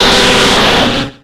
Cri de Nostenfer dans Pokémon X et Y.